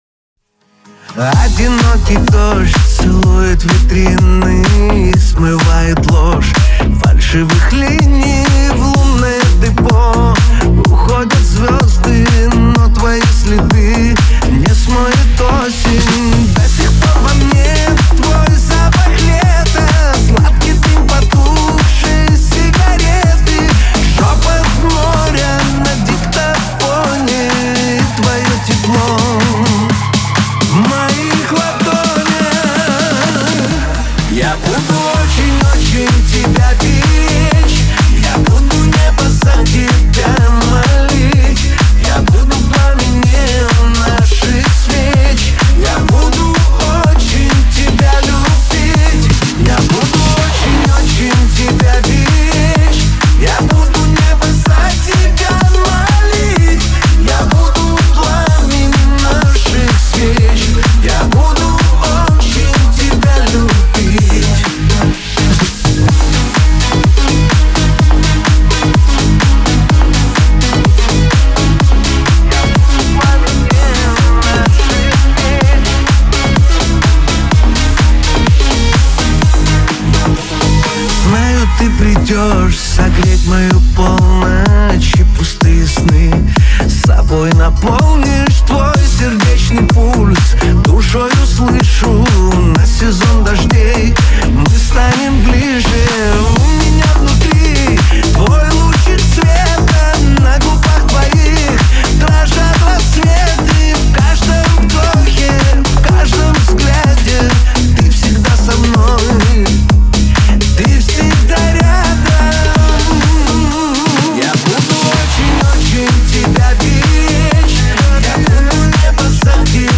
это яркая и эмоциональная композиция в жанре поп